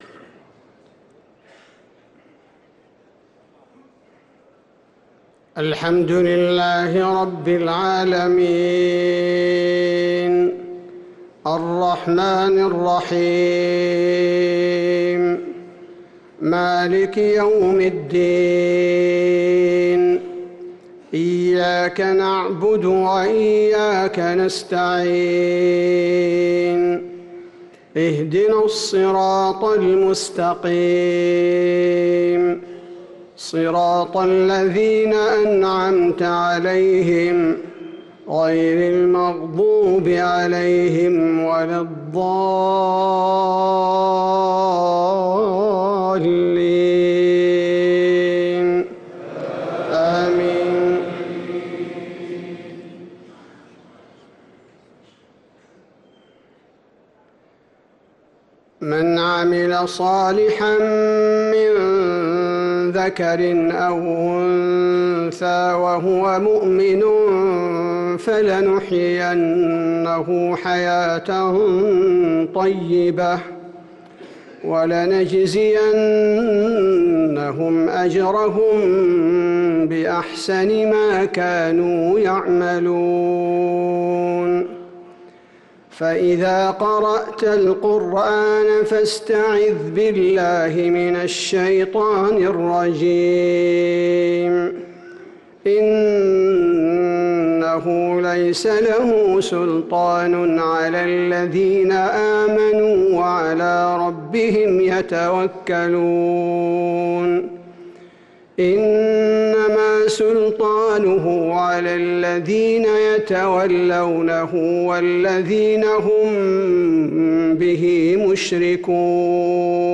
صلاة العشاء للقارئ عبدالباري الثبيتي 3 شعبان 1445 هـ
تِلَاوَات الْحَرَمَيْن .